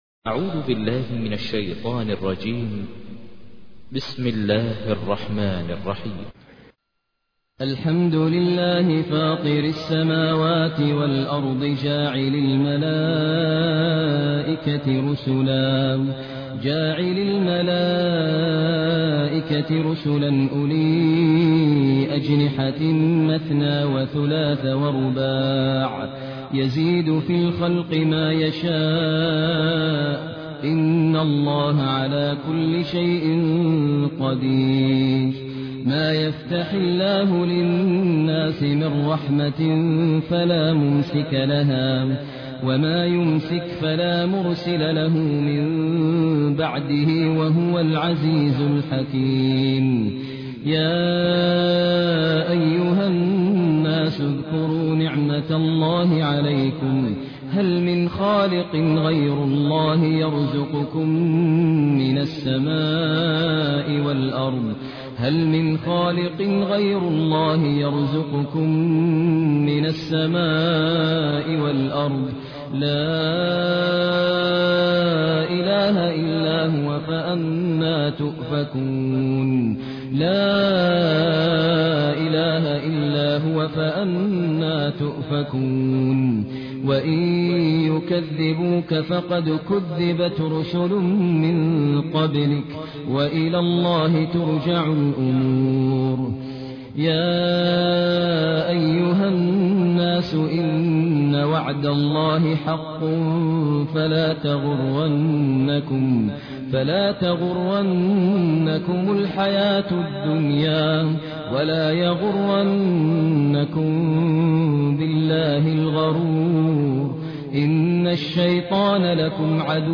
تحميل : 35. سورة فاطر / القارئ ماهر المعيقلي / القرآن الكريم / موقع يا حسين